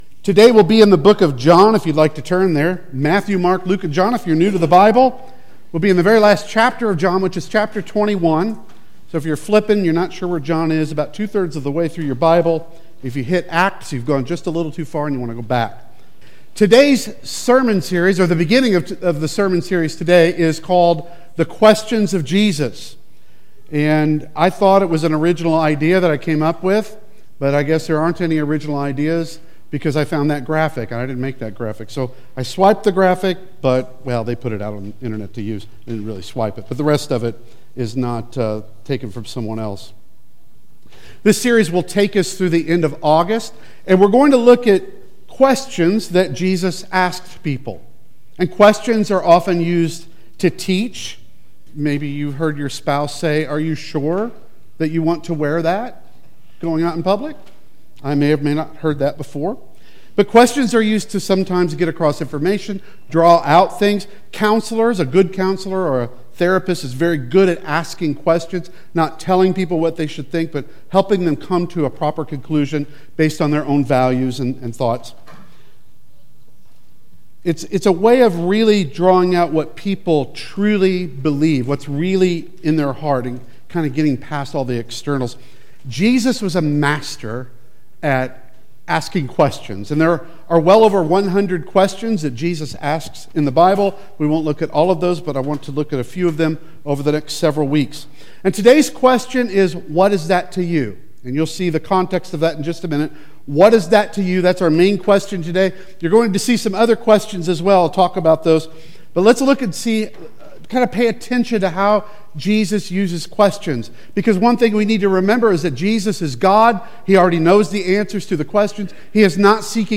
The Questions of Jesus Service Type: Sunday Worship Service Preacher